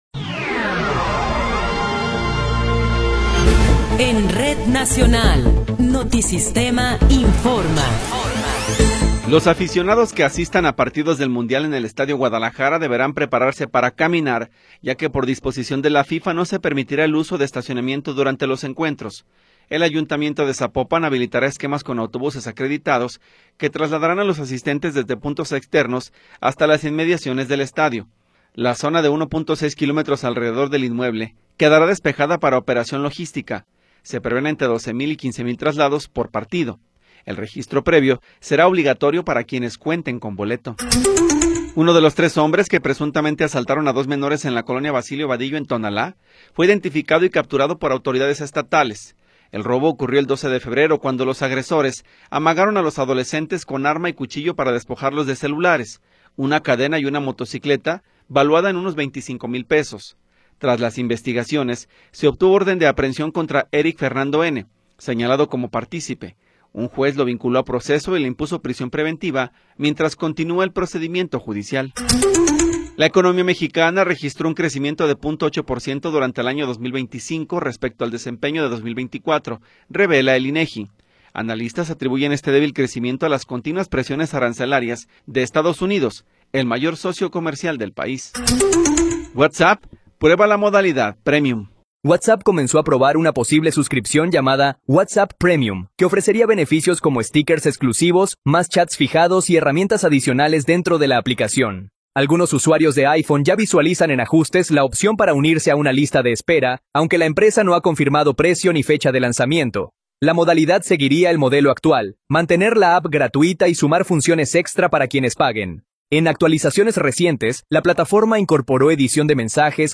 Noticiero 15 hrs. – 28 de Febrero de 2026
Resumen informativo Notisistema, la mejor y más completa información cada hora en la hora.